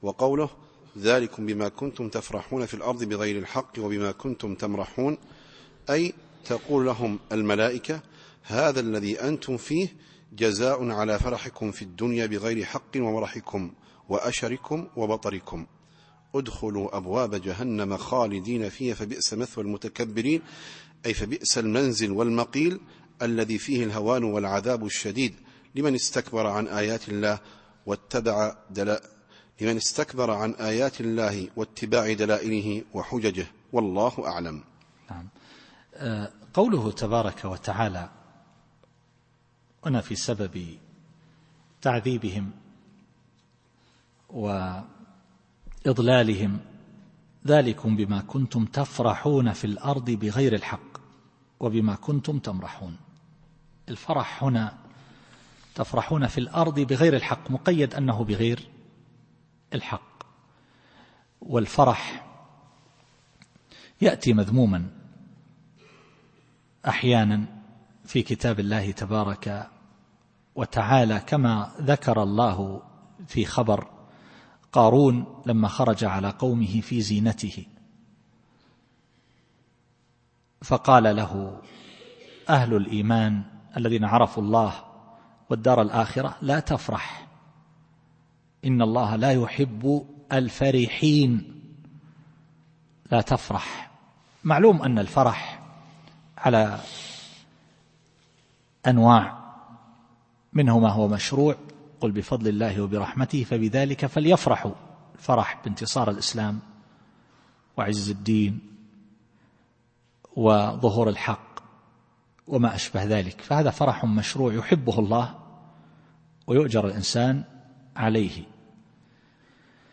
التفسير الصوتي [غافر / 75]